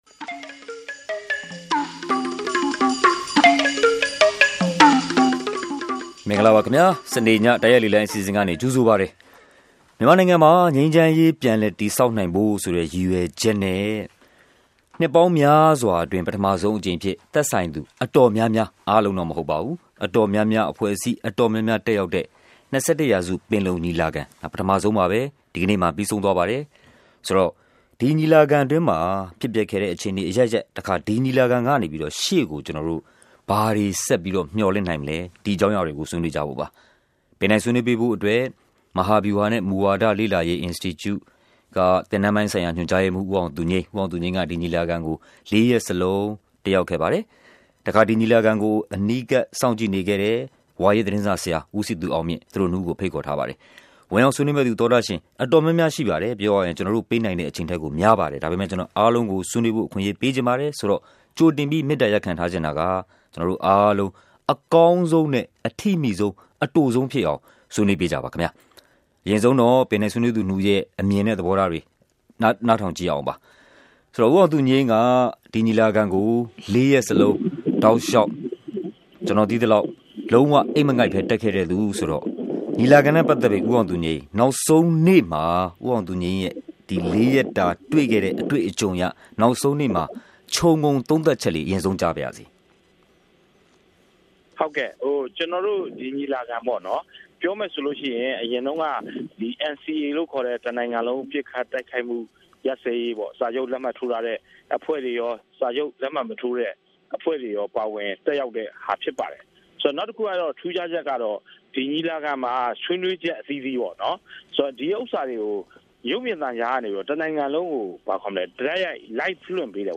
ကျင်းပပြီးစီးသွားတဲ့ ၂၁ ရာစုပင်လုံညီလာခံနဲ့ပတ်သက်ပြီး လေ့လာသူတွေရဲ့ ခြုံငုံသုံးသပ်ချက်၊ အားရစရာ အကောင်းဆုံးနဲ့ အားမရစရာဖြစ်ခဲ့တာတွေ၊ ရှေ့အနာဂတ်အလားအလာ စတာတွေကို ပင်တိုင်ဆွေးနွေး သူတွေနဲ့အတူ သောတရှင်တွေ တိုက်ရိုက် ပါဝင် ဆွေးနွေးထားပါတယ်။